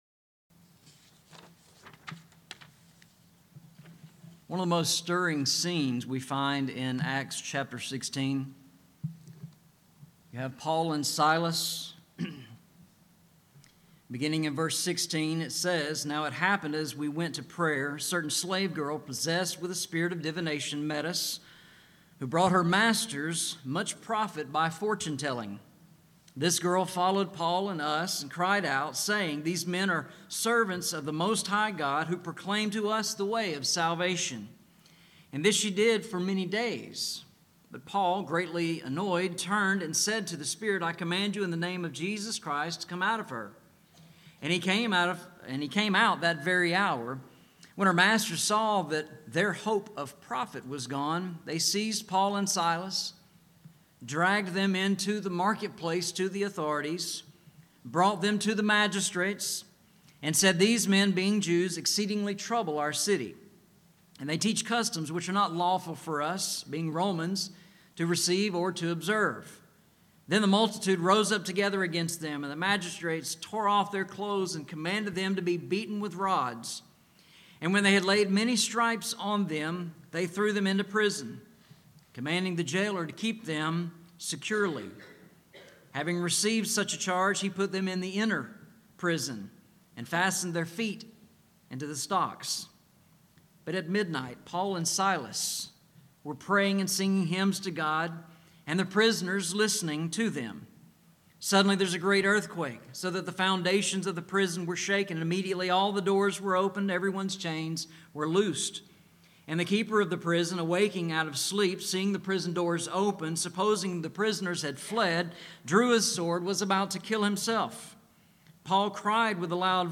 Scripture, Singing, and Prayer Service Devotional